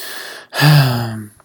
sigh.wav